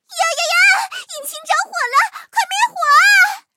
M4谢尔曼中破语音.OGG